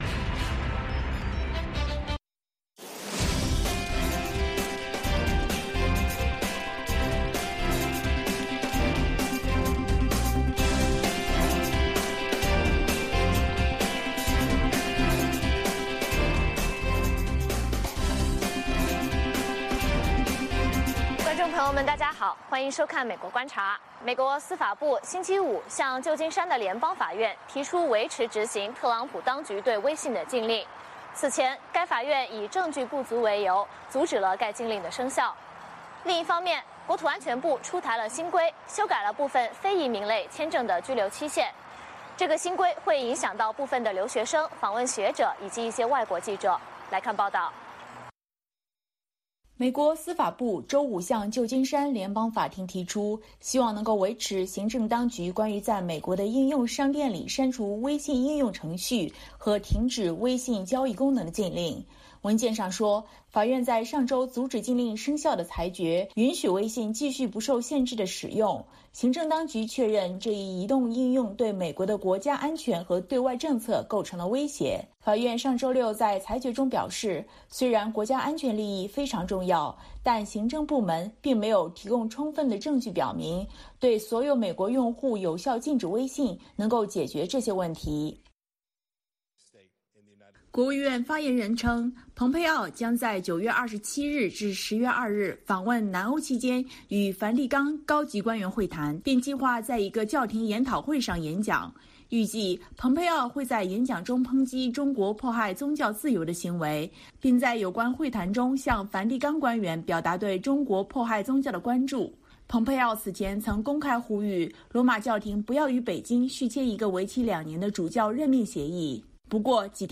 北京时间早上6-7点广播节目，电视、广播同步播出VOA卫视美国观察。
节目邀请重量级嘉宾参与讨论。